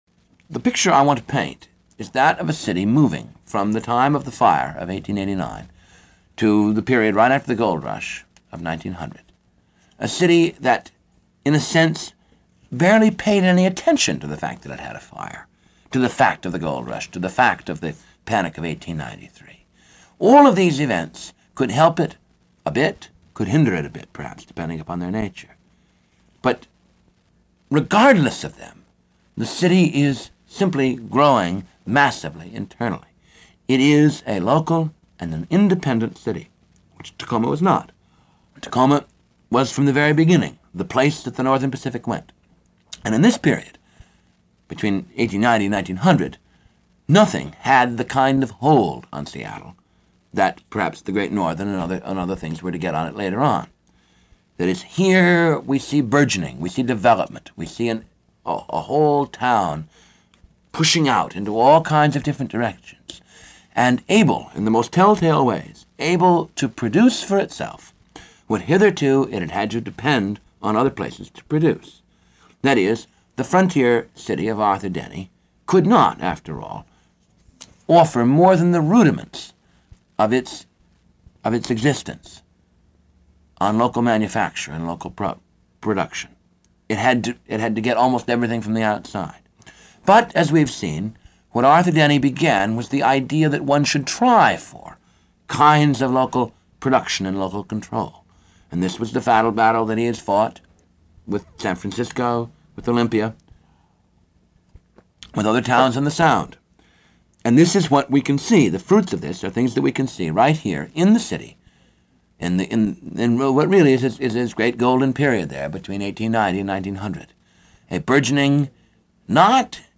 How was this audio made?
a 21-part series broadcast on KRAB radio in 1970 and rebroadcast in 1975.